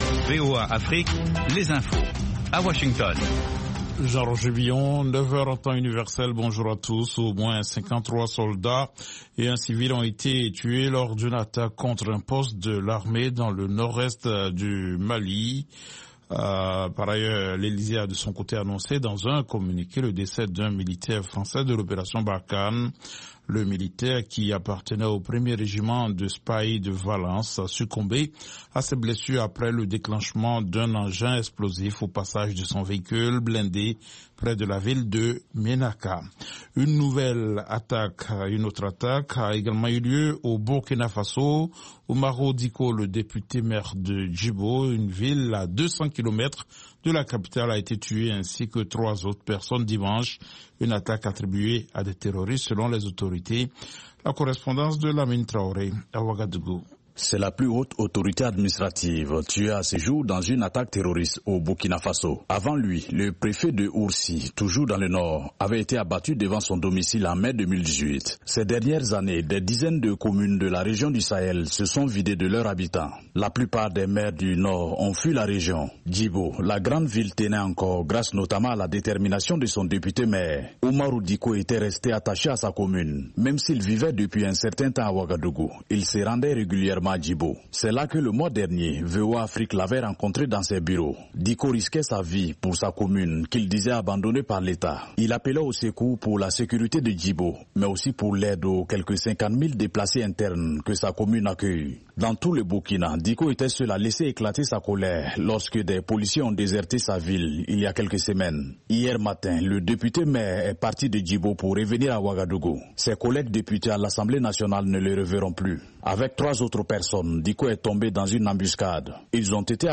5 min News French